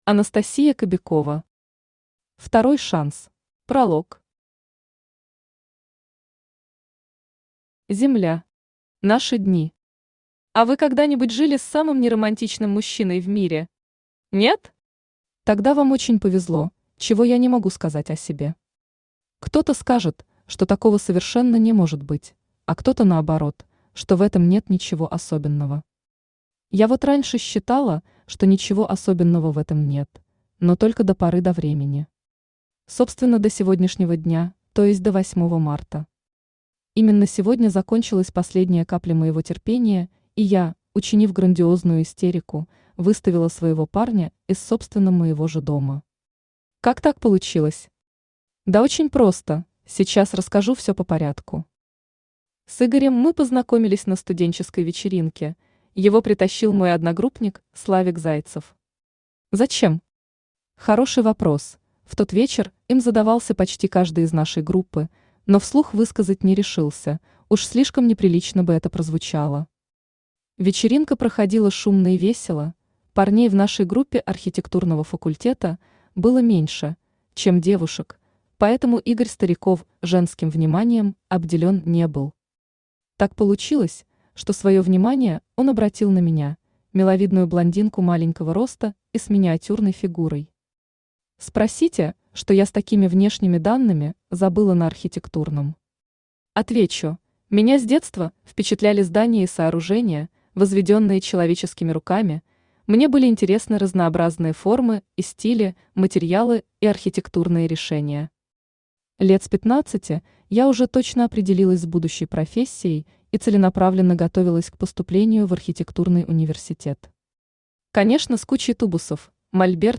Аудиокнига Второй шанс